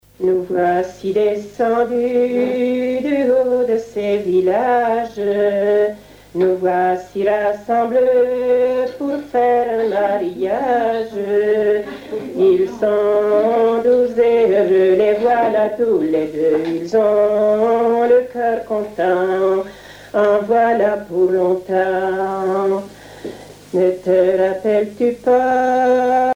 circonstance : fiançaille, noce
Genre strophique
Pièce musicale éditée